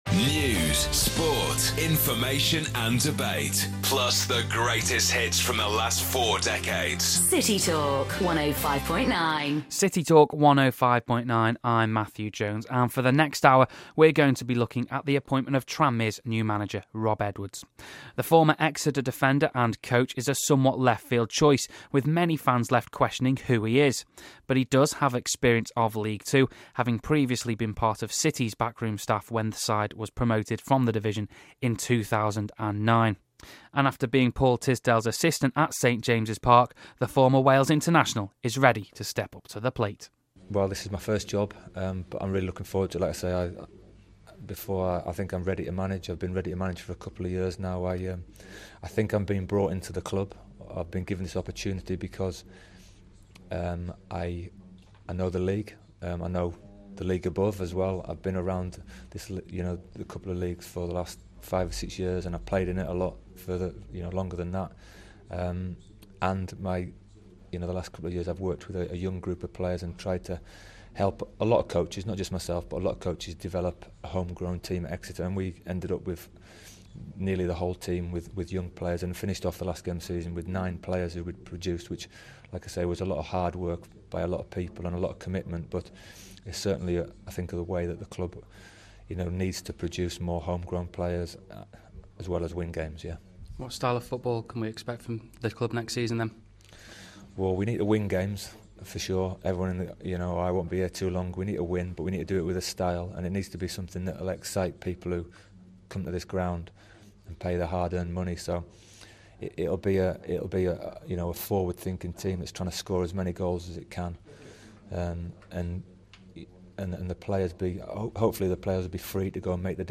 Listen to an exclusive broadcast on City Talk 105.9 about Tranmere's new boss Rob Edwards.